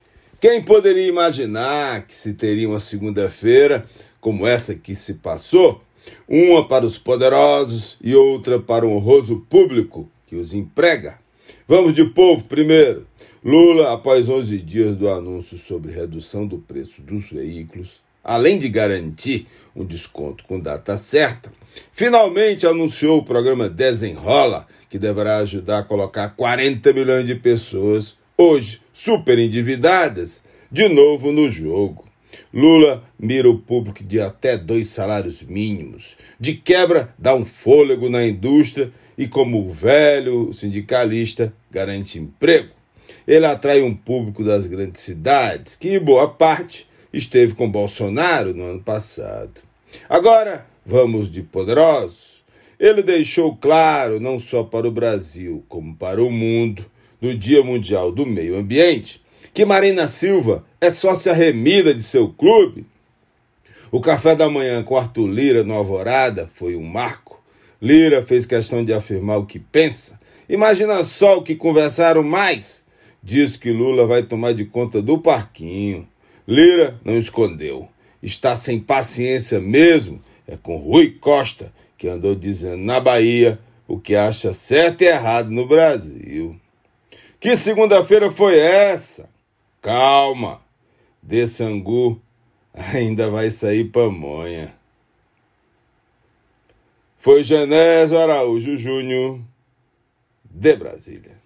Comentário desta terça-feira